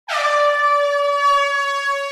Chicharra Efeito Sonoro: Soundboard Botão